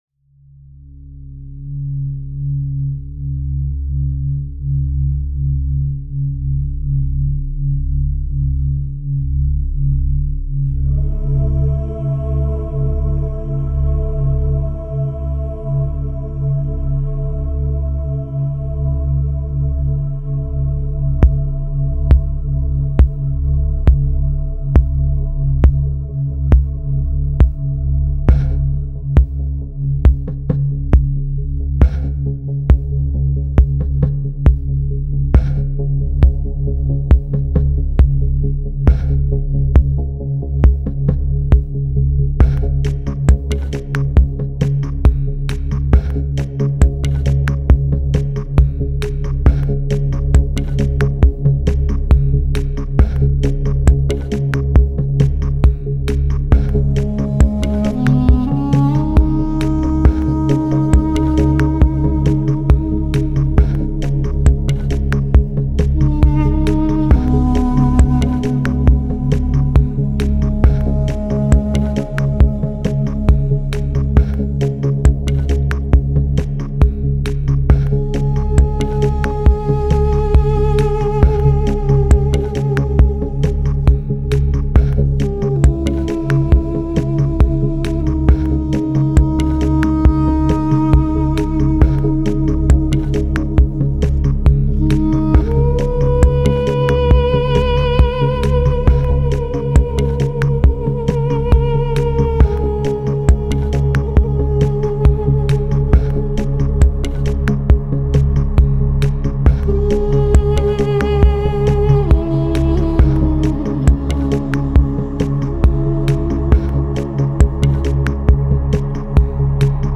Дудук с ударными (закрыта)